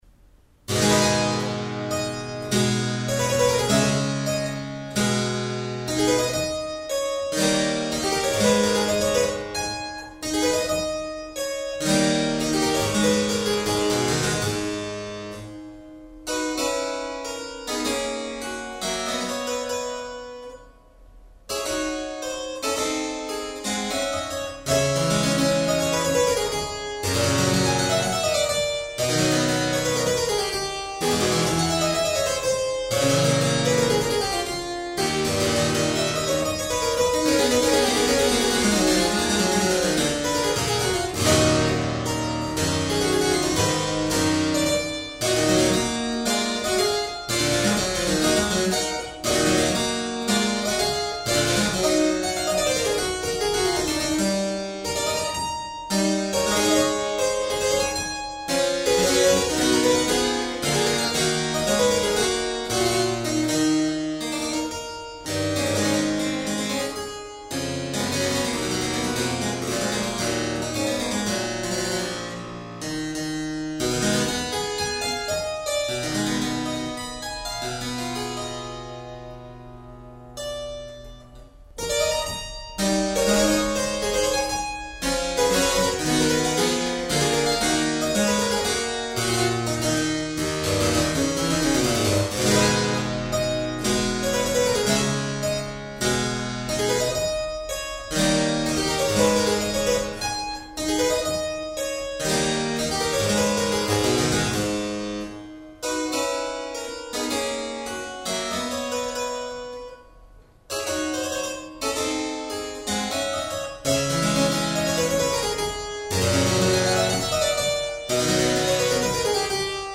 Clavicembalo da Michael Mietke